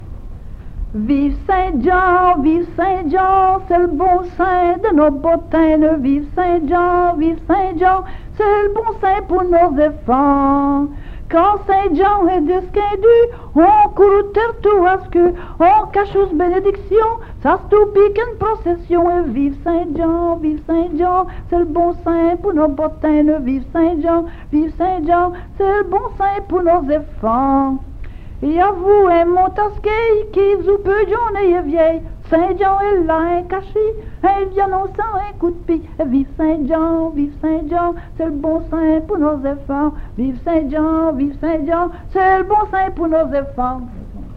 Genre : chant
Type : chanson narrative ou de divertissement
Lieu d'enregistrement : Jolimont
Support : bande magnétique